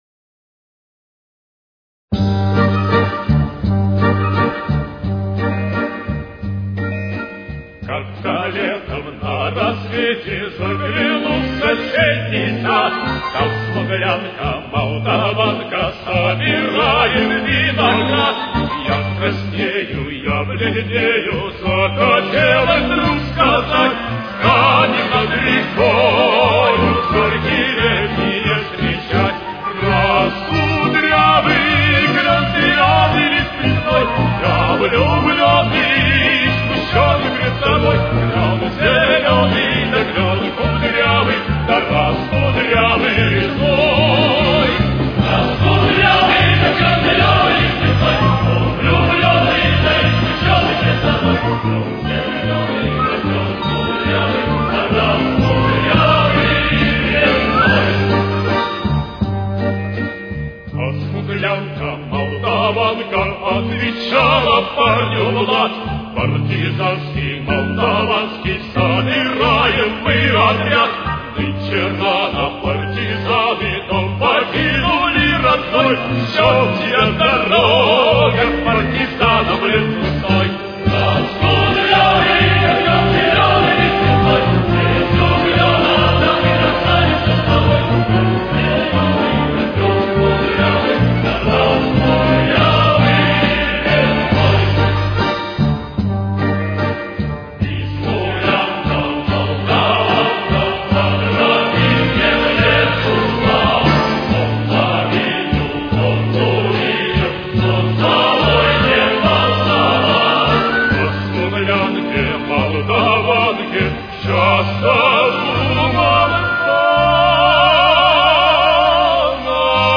Соль минор. Темп: 89.